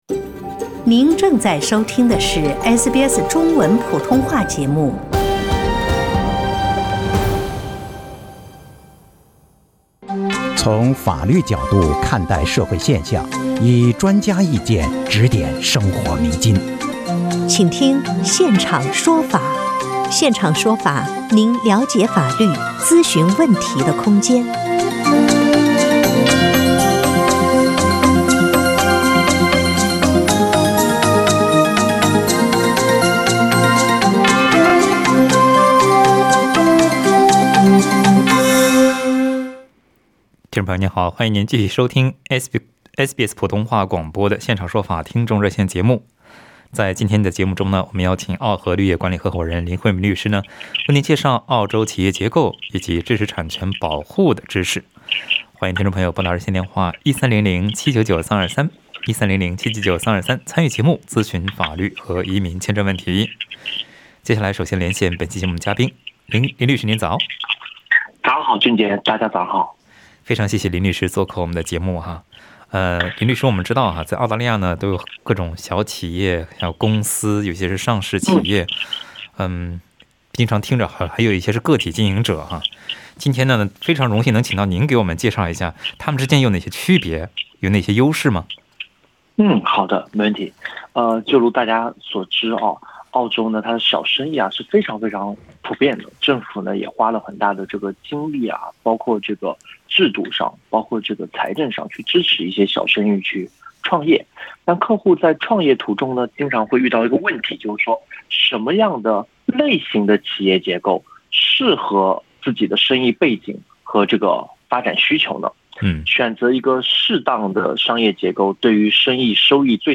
在本期《现场说法》听众热线节目中，听友们还咨询了租客未付疫情期间数月房租、未接种疫苗遭雇主解雇、财产转移、网上攻击他人是否被起诉等问题，